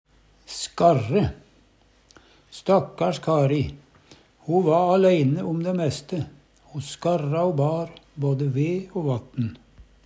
skarre - Numedalsmål (en-US)